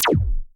sounds_laser_05.ogg